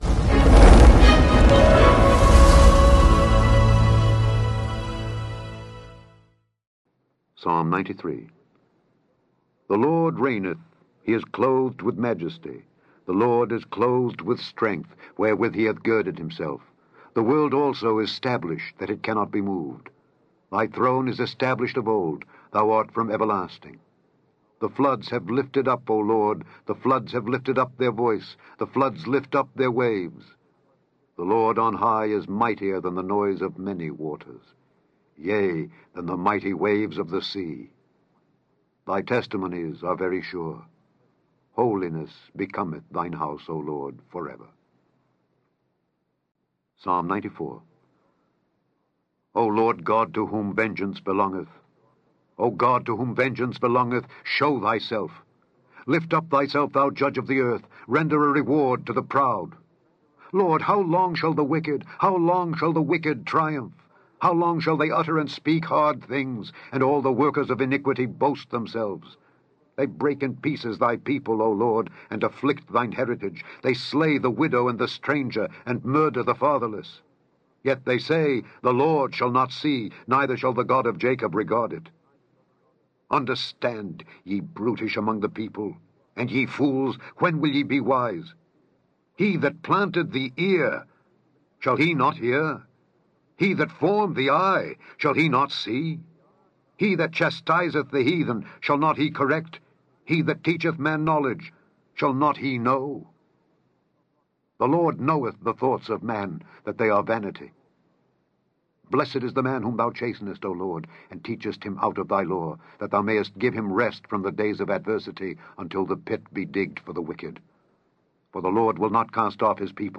Click on the podcast to hear Alexander Scourby read Psalms 93-95.